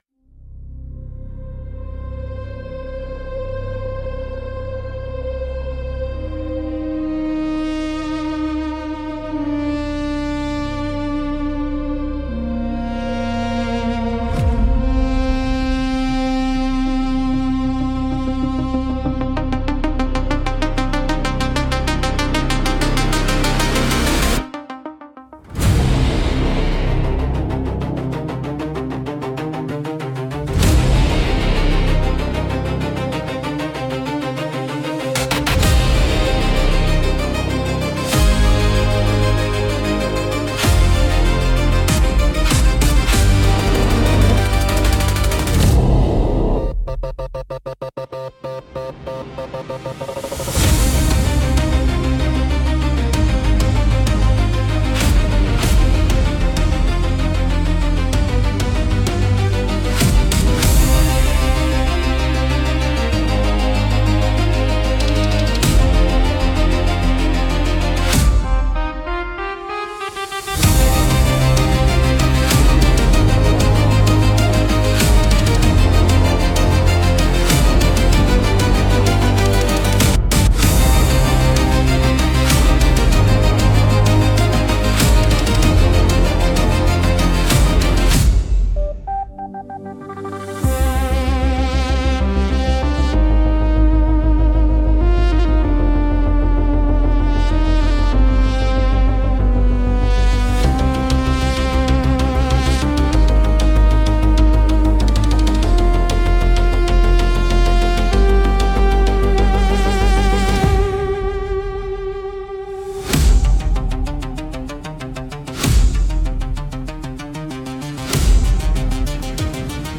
Sci-FI / Action
Mood: Epic, Sci-Fi, Suspenseful, Action